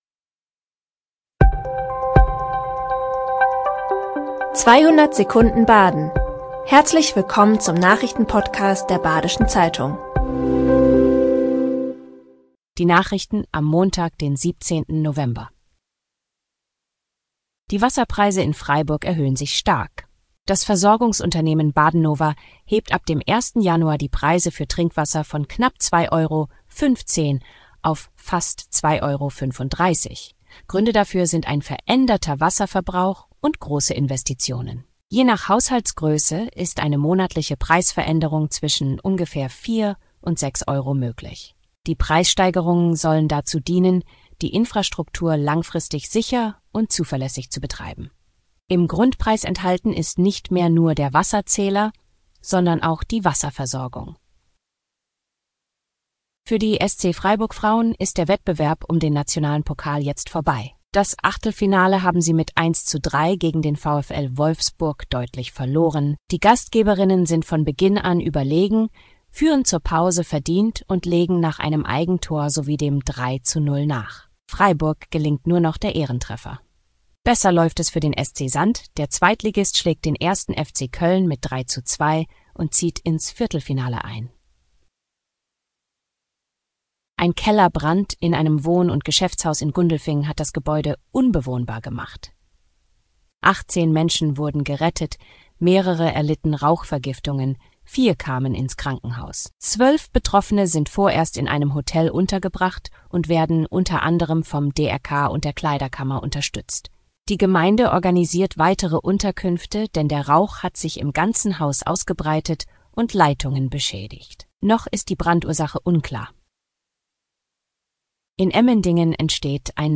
5 Nachrichten in 200 Sekunden.